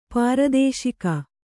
♪ pāradēśika